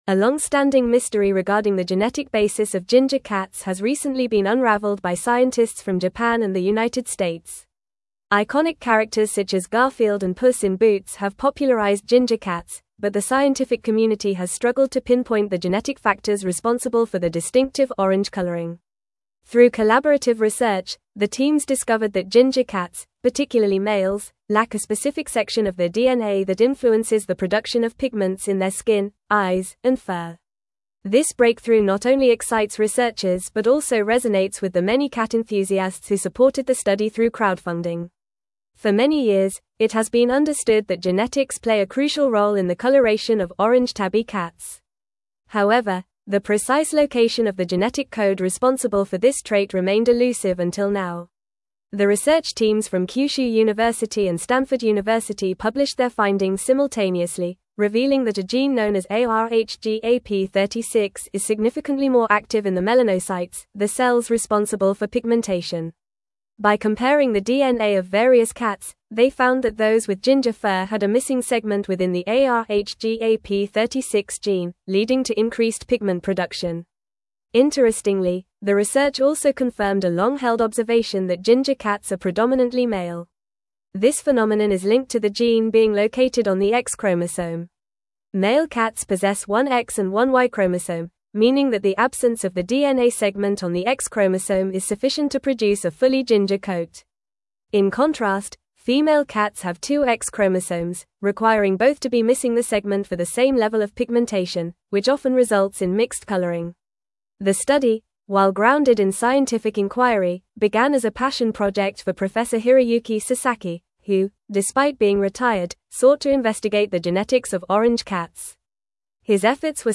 Fast
English-Newsroom-Advanced-FAST-Reading-Genetic-Mystery-of-Ginger-Cats-Unveiled-by-Researchers.mp3